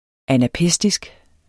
Udtale [ anaˈpεsdisg ]